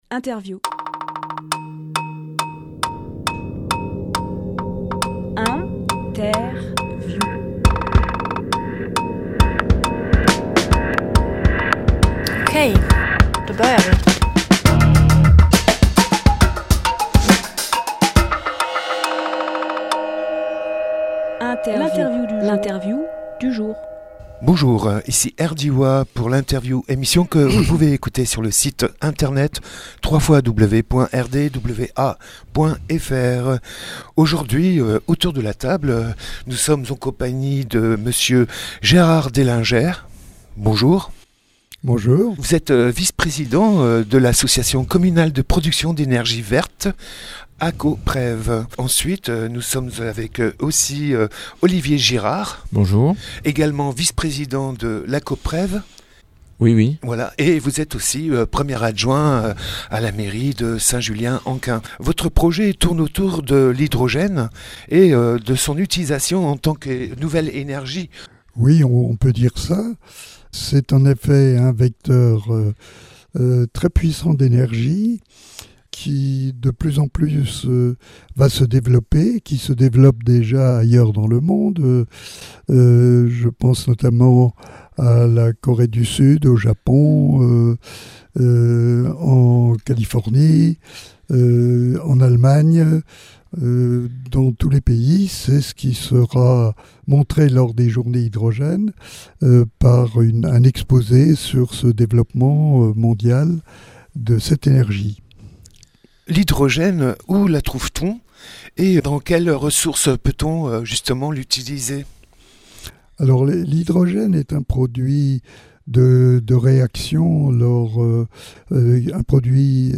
Emission - Interview Journées Hydrogène à Saint-Julien-en-Quint Publié le 23 avril 2018 Partager sur…
20.04.18 Lieu : Studio RDWA Durée